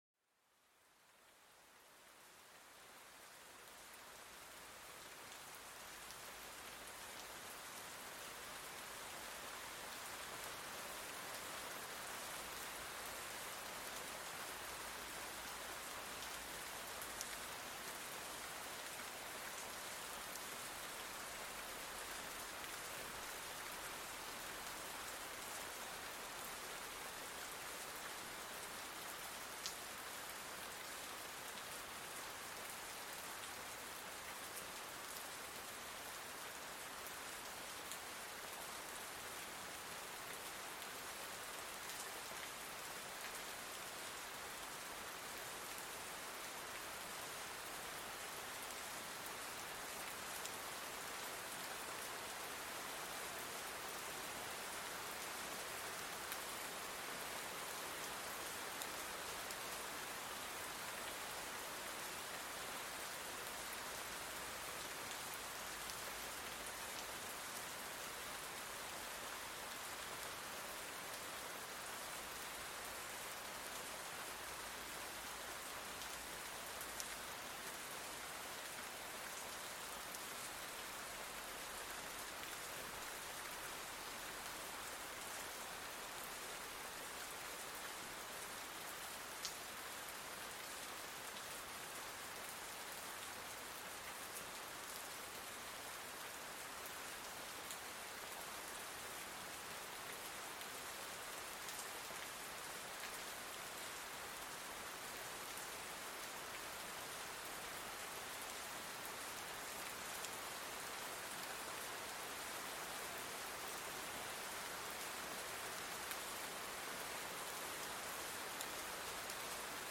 Douce Pluie pour la Relaxation et le Sommeil
Plongez dans la sérénité avec le doux son de la pluie apaisante, idéale pour se détendre et s'endormir. Les gouttes qui caressent les feuilles et les flaques créent une symphonie naturelle apaisante.